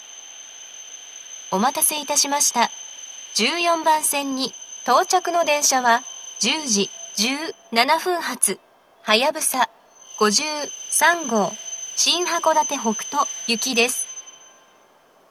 １４番線到着放送
接近放送及び到着放送は「はやぶさ５３号　新函館北斗行」です。